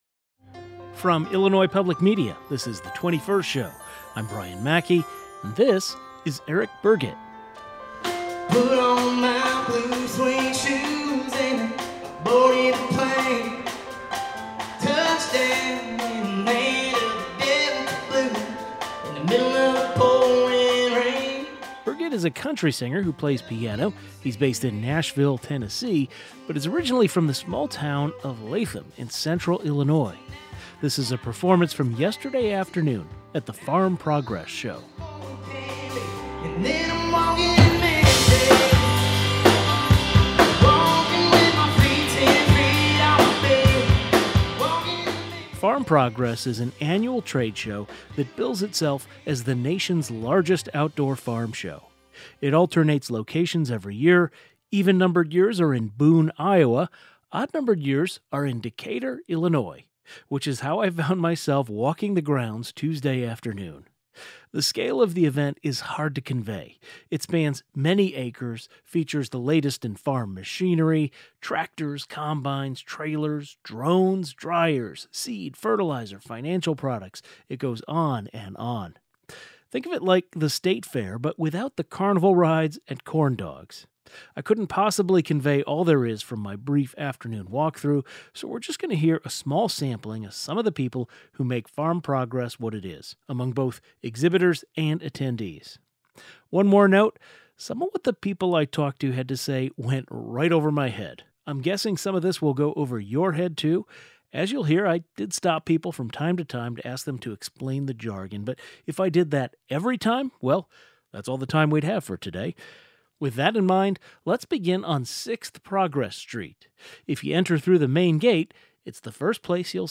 Voices from 2025 Farm Progress Show